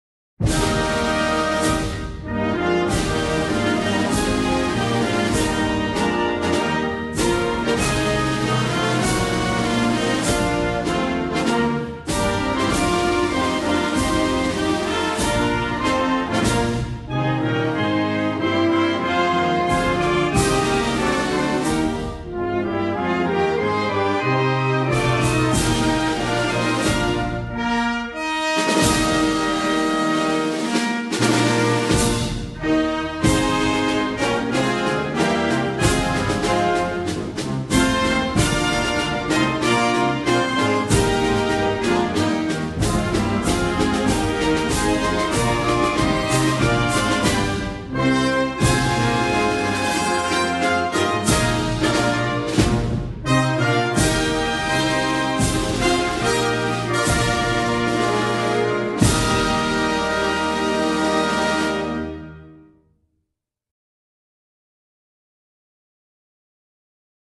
Independent_and_Liberty_Federal_Republic_of_Baijania_Instrumental.ogg